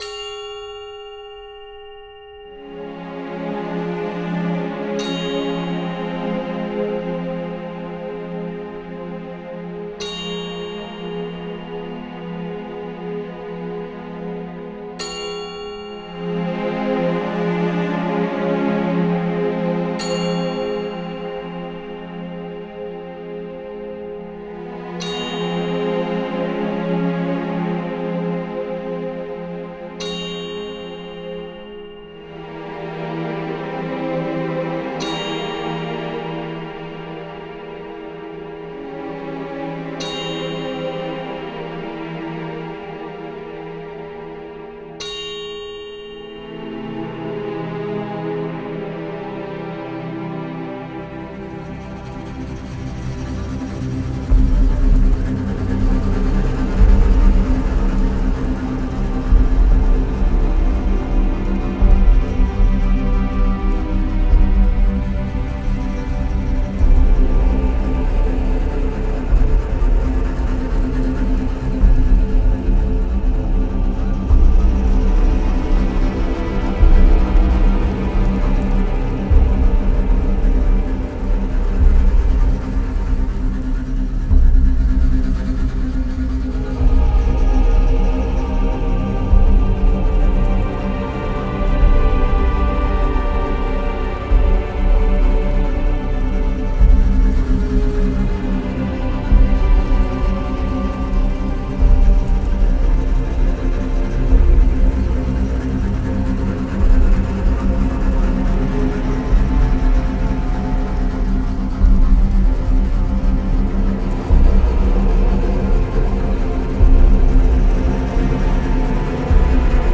Ich habe den Soundtrack komponiert, cinematisch chaotisch nicht-rytmisch, wie in der Bucht die Pilotwale Angst, Stress und Chaos erlebten als sie von den Monstern terrorisiert und misshandelt wurden.